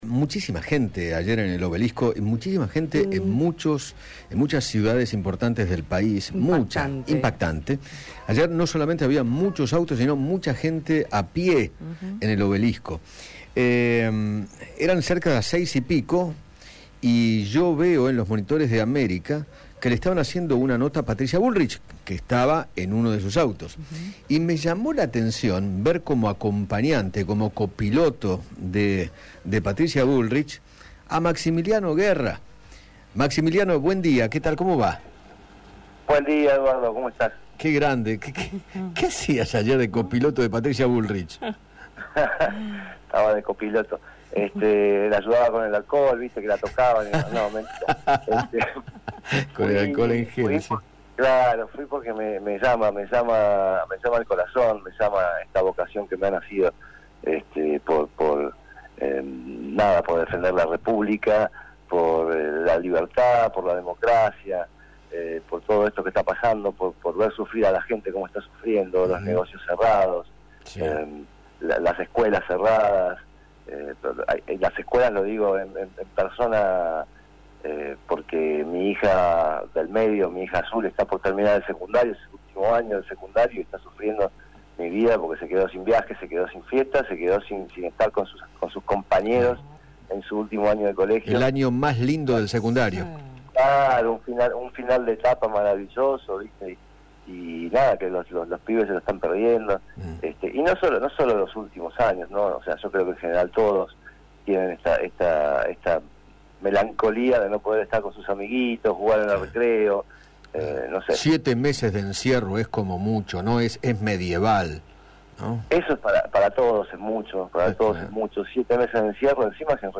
El bailarín Maximiliano Guerra, quien fue copilito de Patricia Bullrich en la marcha de ayer, dialogó con Eduardo Feinmann acerca de las razones que lo llevaron a asistir al banderazo en el Obelisco y criticó los dichos del Jefe de Gabinete, Santiago Cafiero.